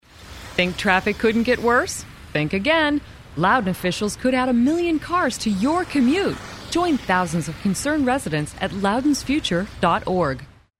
LoudounAd2female.mp3